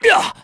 Roman-Vox_Damage_kr_02.wav